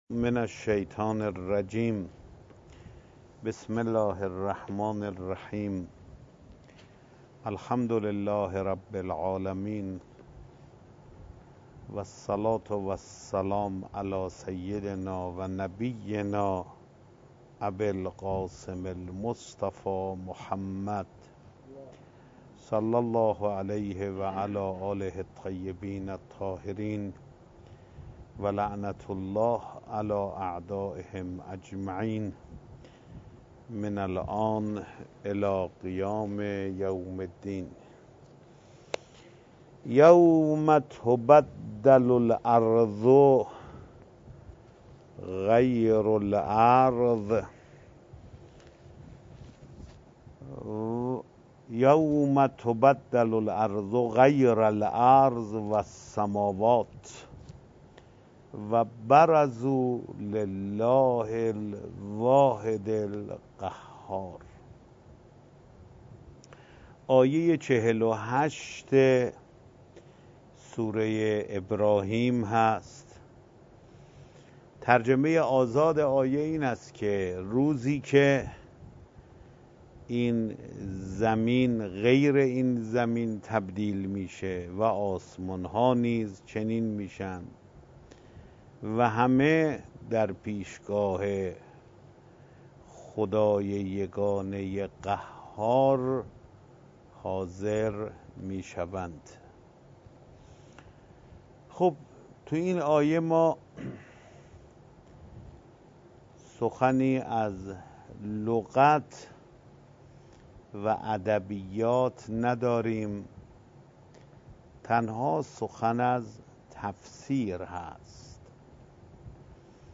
جلسه تفسیر قرآن کریم حجت‌الاسلام سیداحمد خاتمی با محوریت سوره ابراهیم، روز گذشته 17 خردادماه برگزار شد.